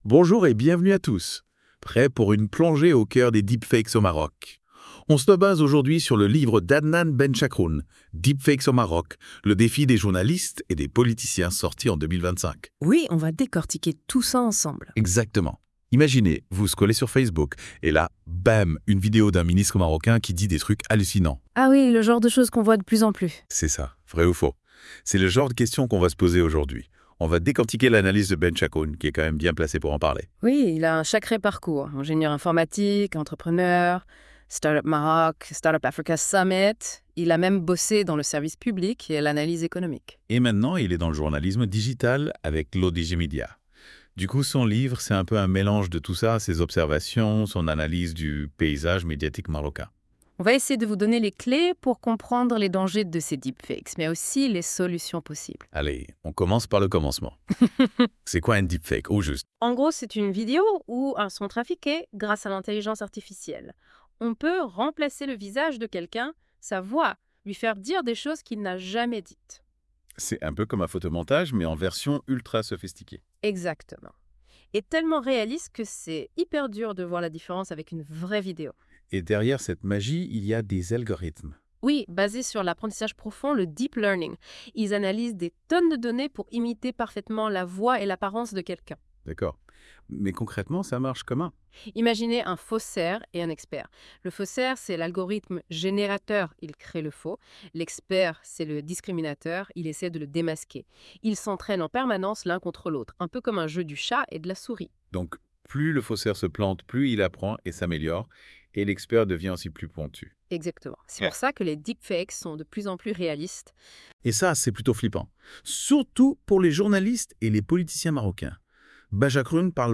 Débat (48.09 Mo) 1. Que sont précisément les deepfakes et comment fonctionnent-ils ? 2. Quels sont les principaux défis que les deepfakes posent aux journalistes marocains ? 3.